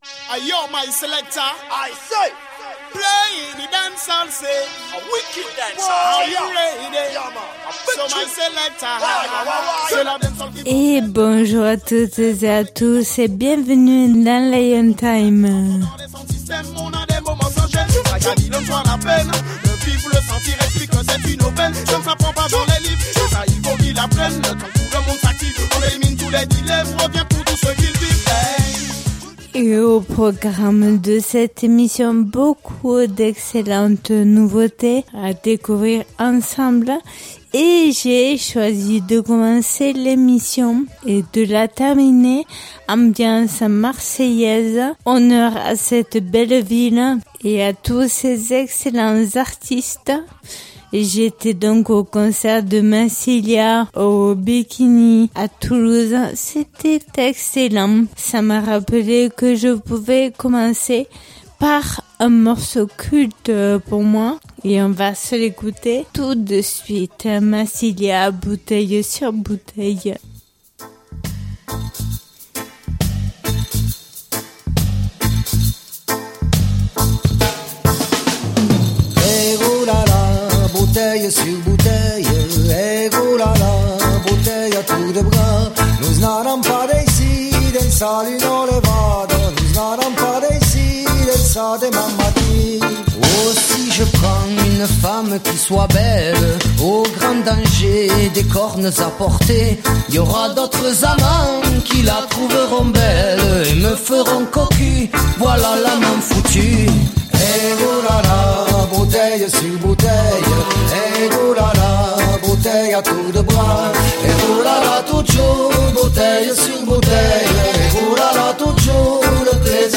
Émission qui invite au voyage et à la découverte du roots, du reggae et du dancehall.